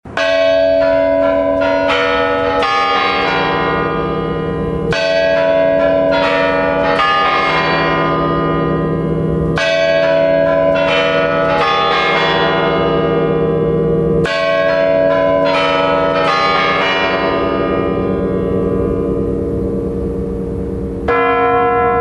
Бой курантов в Новый год